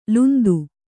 ♪ lundu